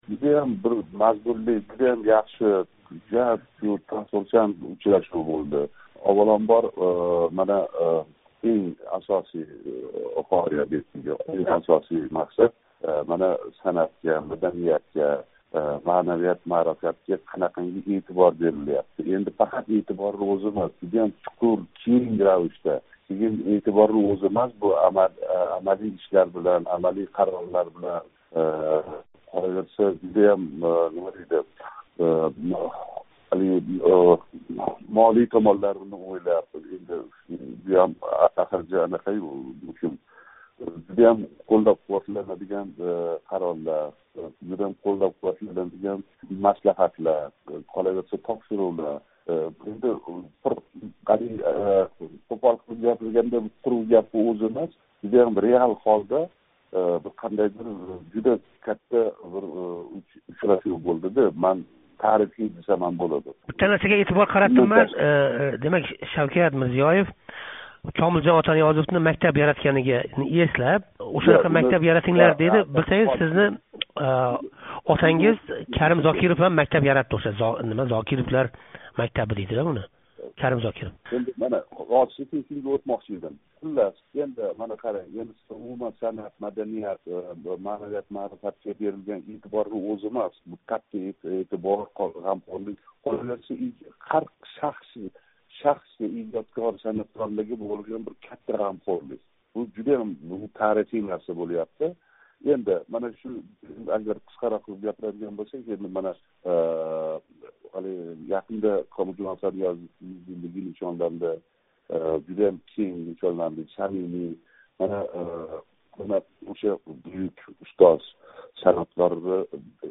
Фаррух Зокиров билан суҳбат